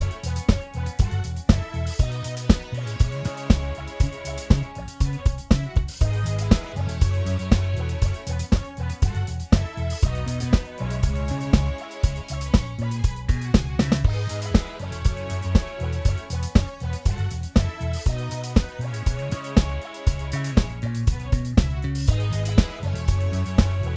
Minus All Guitars Dance 4:10 Buy £1.50